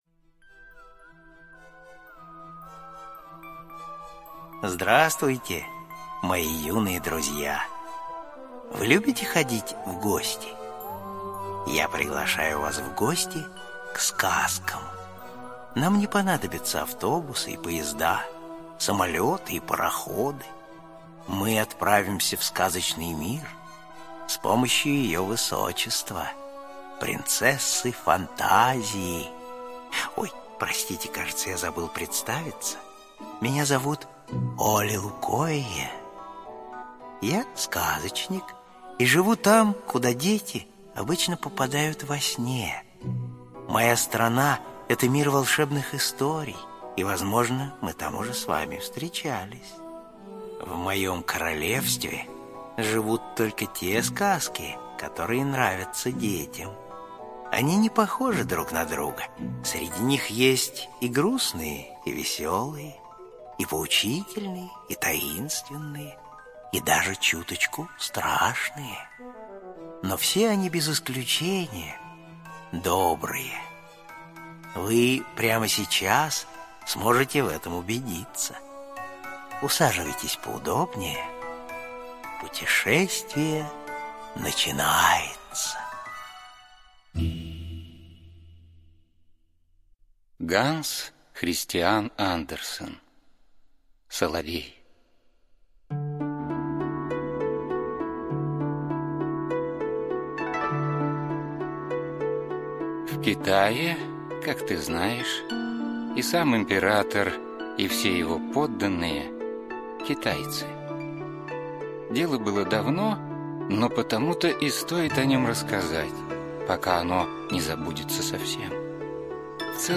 Соловей - аудиосказка Андерсена. Сказка о самом лучшем певце из всех птиц — соловье. Соловей пел в лесу рядом с садом китайского императора.